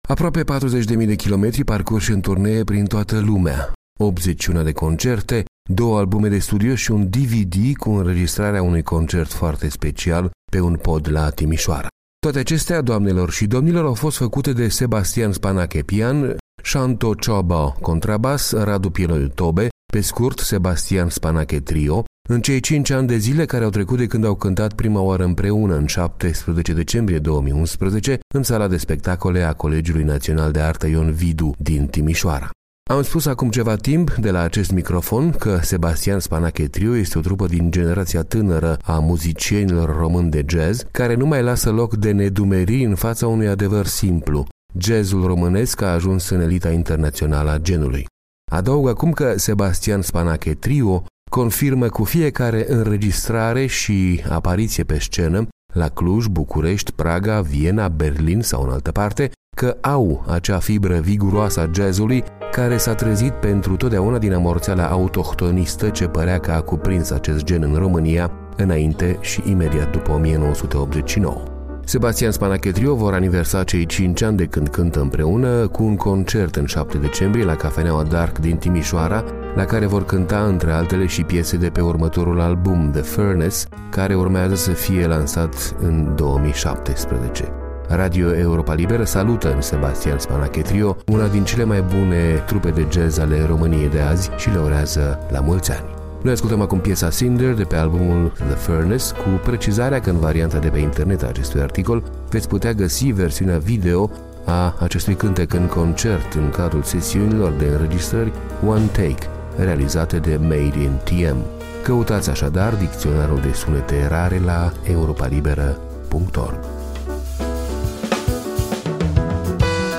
Au acea fibră viguroasă a jazz-ului care s-a trezit pentru totdeauna din amorțeala autohtonistă ce părea că a cuprins acest gen în România, înainte și imediat după 1989.